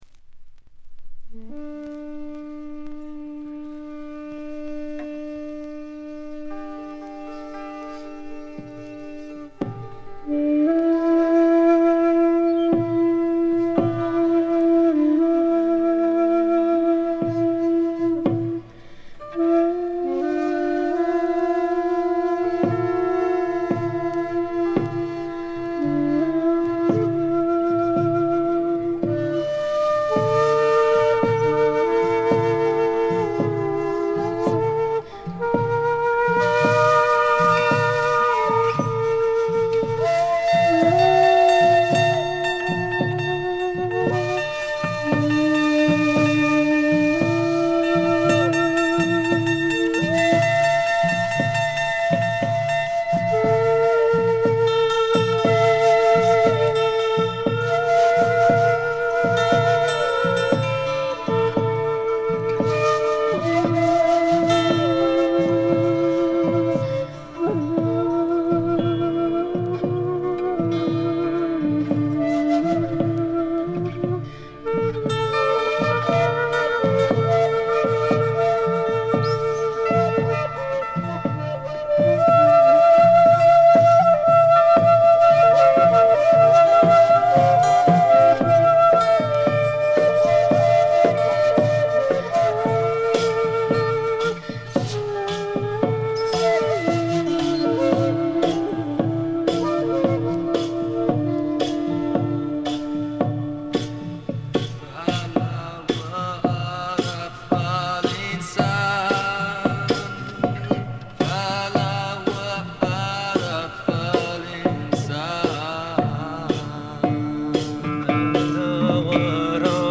静音部で軽微なバックグラウンドノイズ、チリプチ。
試聴曲は現品からの取り込み音源です。
Drums
Guitar
Vocals, Shakuhachi
Zither